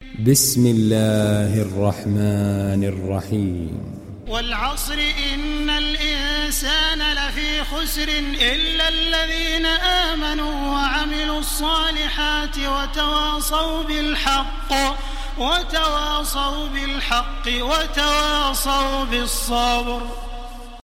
İndir Asr Suresi Taraweeh Makkah 1430